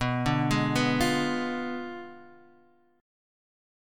Bdim7 chord